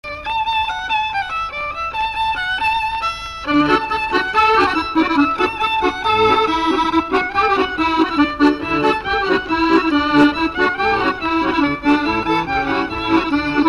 Maraîchine
danse : branle : courante, maraîchine
Incipit du couplet Instrumental
Pièce musicale inédite